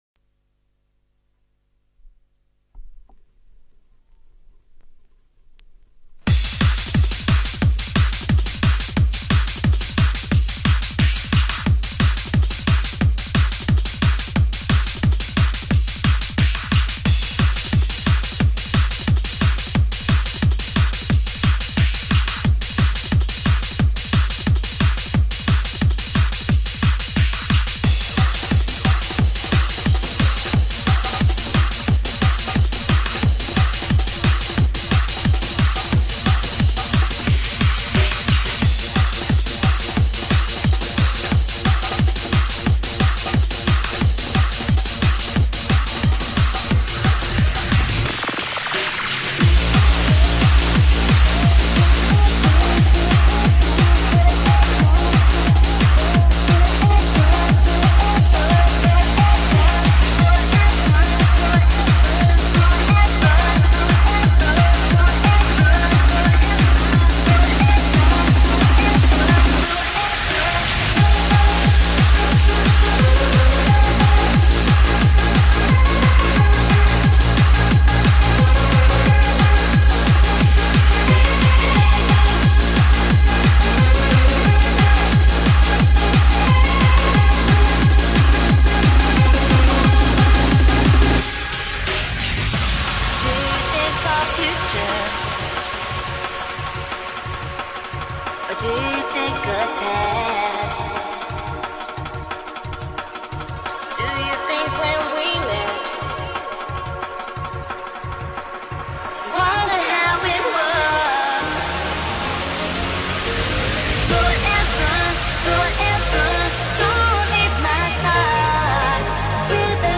Hardcore Happy Hardcore Breaks